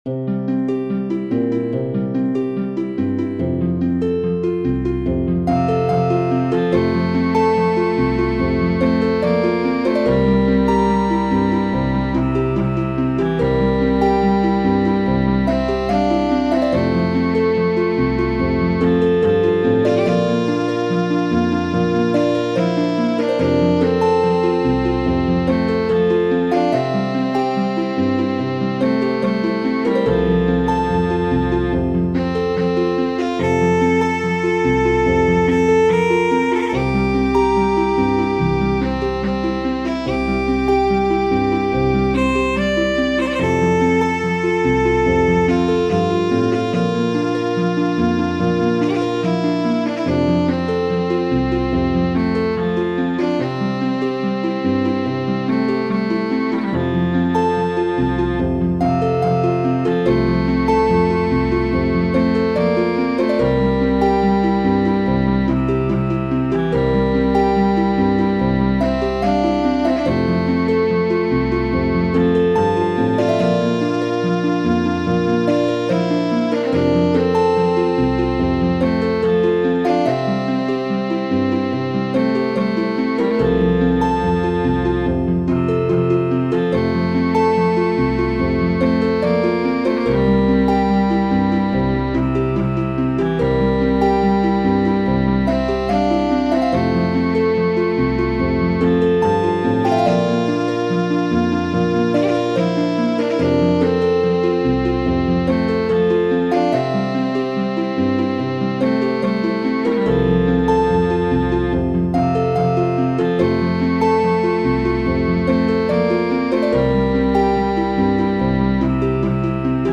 Here's a traditional Scottish Country Dance tune.... It is commonly played in F, so I've left it like that, but if anyone wants it transposed to their preferred key, let me know.
Additionally, here's a harp and viola arrangement, found on the MuseScore site to play along to -
Very pretty melody thanks for posting.
Dumbartons_Drums_for_Viola__Harp_.mp3